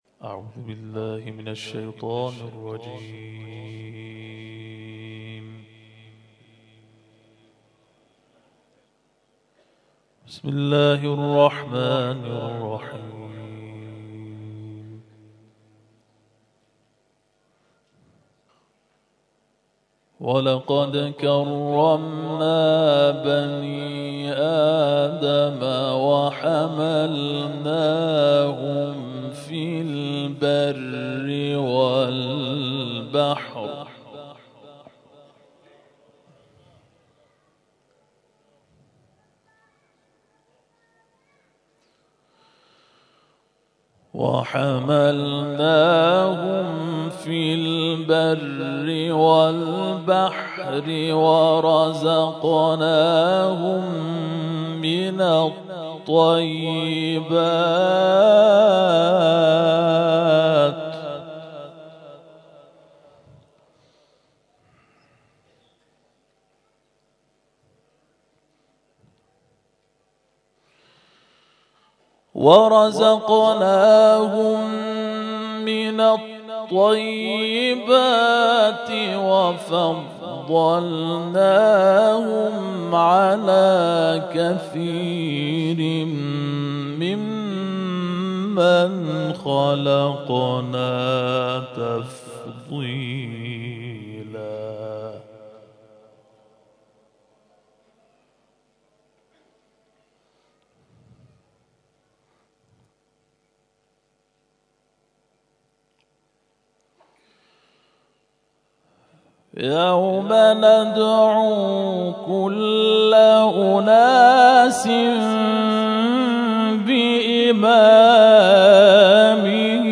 گروه جلسات و محافل ــ کرسی‌های تلاوت نفحات‌القرآن ویژه دهه فجر با تلاوت 12 قاری ممتاز و بین‌المللی در نقاط مختلف شهر تهران همراه با اهدای جوایز و طرح حفظ موضوعی قرآن برگزار شد.